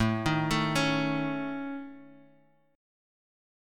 AMb5 chord